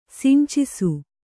♪ sincisu